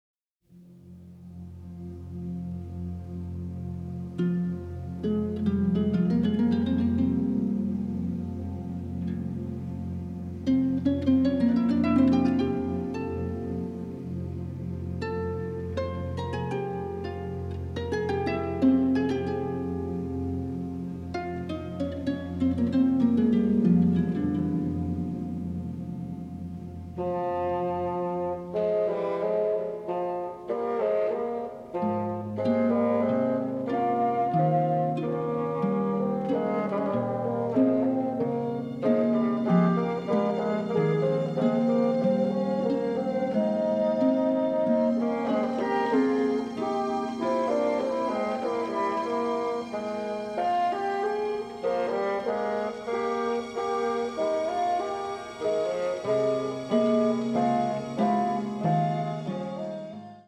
colorful orchestral tapestry is a true exotic delight.